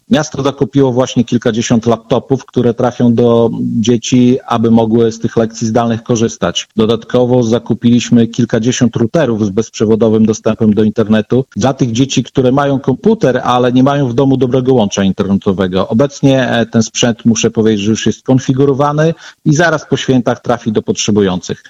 – Musimy oszczędzać – mówił w Radiu 5 dzisiejszy gość (09-04-20) Mirosław Karolczuk, burmistrz Augustowa. Oszczędności w czasie epidemii są koniecznością, do której zmuszeni są samorządowcy z regionu. Jak dodał Karolczuk, miasto rezygnuje między innymi z imprez.